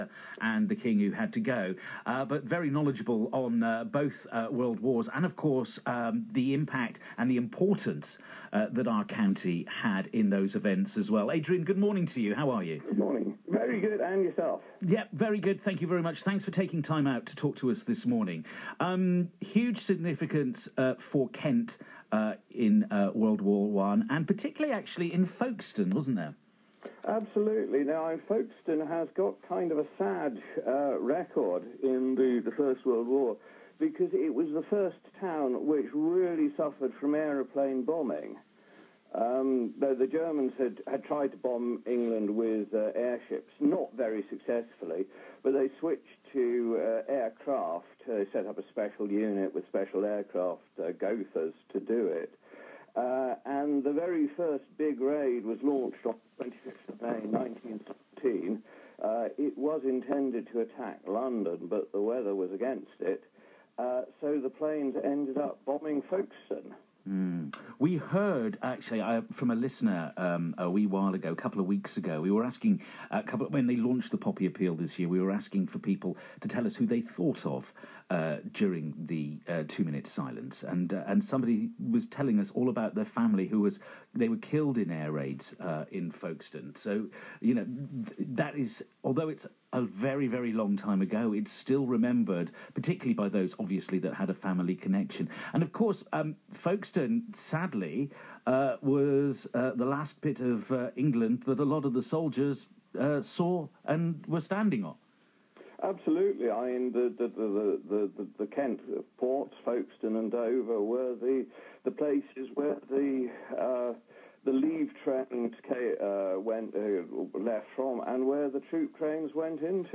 interviewed on BBC Radio Kent about the significance of Remembrance Day